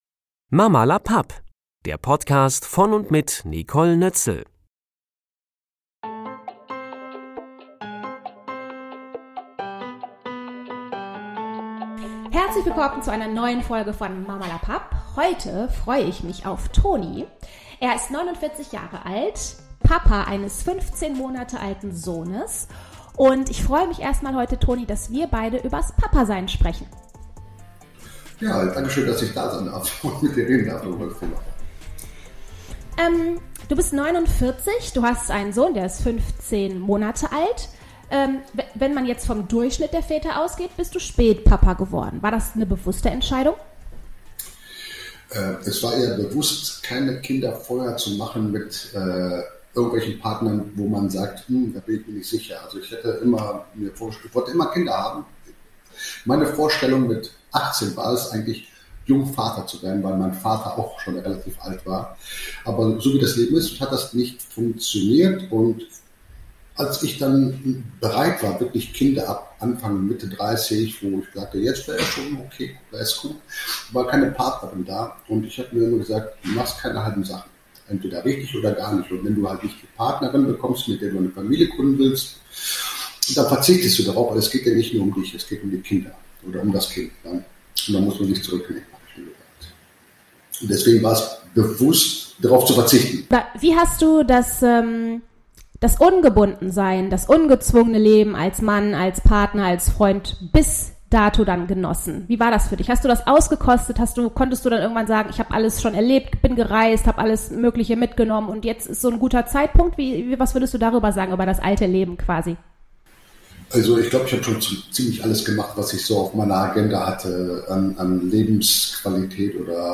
Wie er das Leben als später Vater genießt und was er daran besonders schätzt - das hört Ihr in diesem Gespräch.